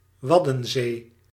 The Wadden Sea (Dutch: Waddenzee [ˈʋɑdə(n)zeː]
Nl-Waddenzee.ogg.mp3